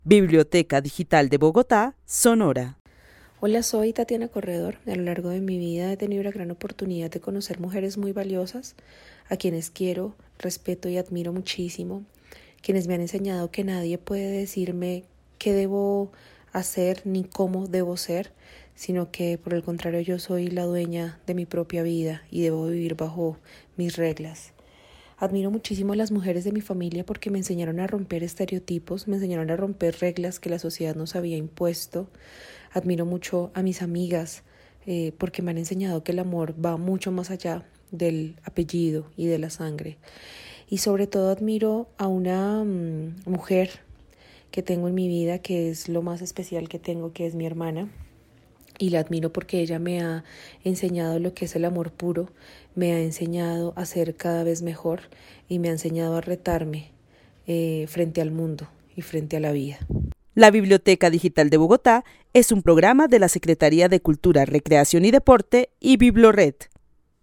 Narración oral de una mujer que vive en la ciudad de Bogotá, quien a lo largo de su vida ha podido conocer gran cantidad de mujeres a las que quiere y admira, y quienes le han enseñado que nadie puede imponerle nada. Admira a las mujeres de su familia porque le enseñaron a romper estereotipos y reglas impuestas por la sociedad.
El testimonio fue recolectado en el marco del laboratorio de co-creación "Postales sonoras: mujeres escuchando mujeres" de la línea Cultura Digital e Innovación de la Red Distrital de Bibliotecas Públicas de Bogotá - BibloRed.